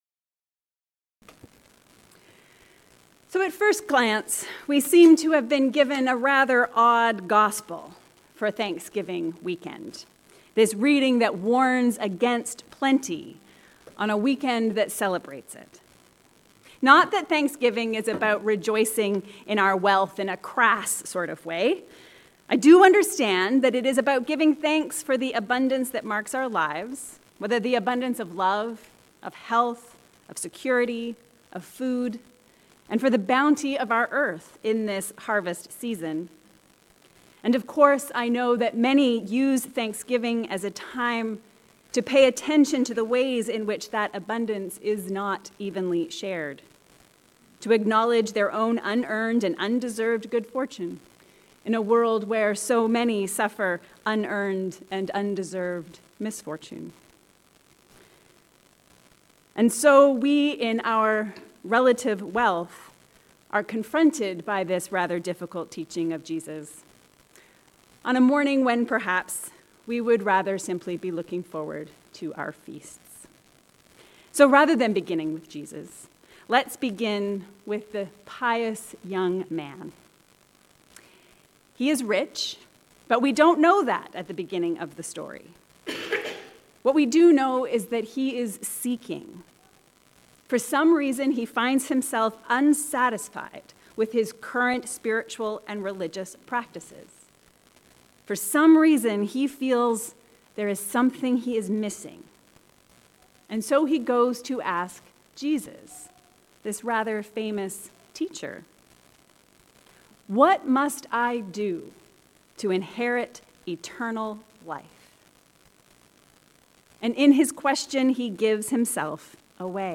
Inheriting Eternal Life. A sermon on Mark 10:17-31